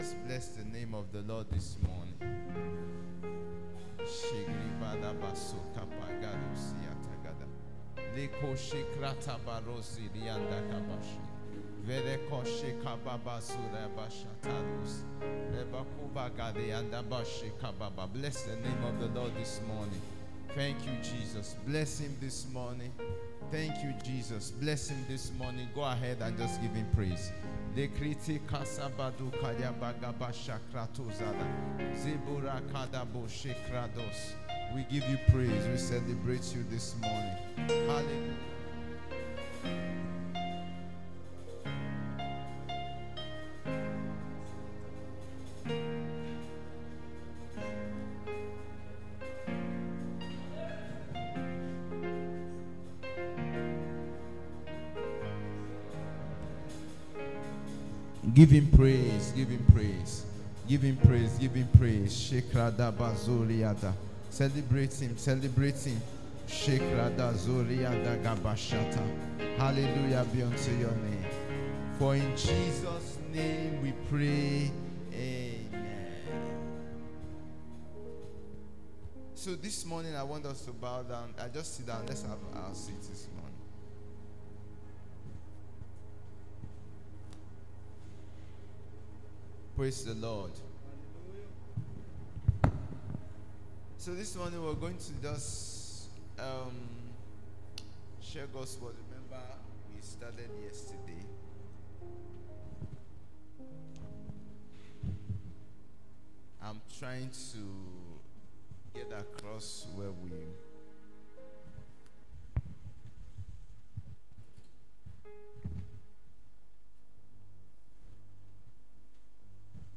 Service Type: possibility Conference All things are possible with God.